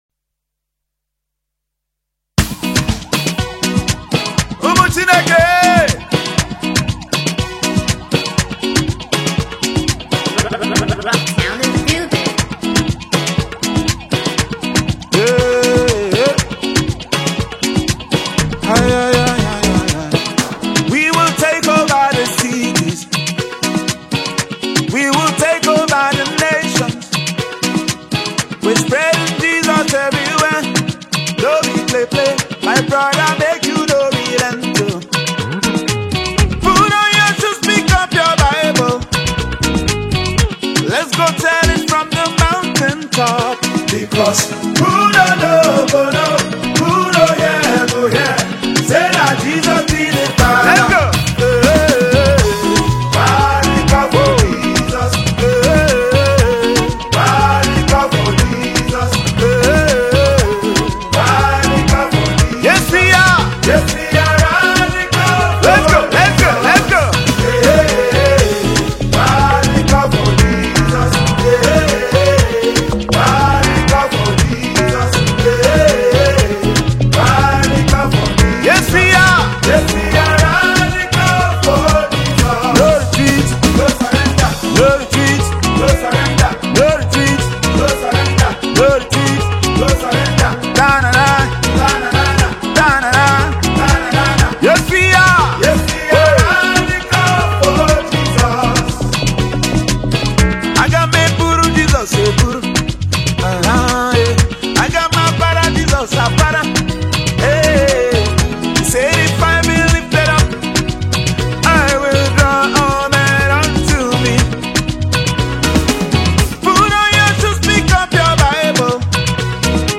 gospel
spiritually uplifting anthem
vocal delivery is filled with passion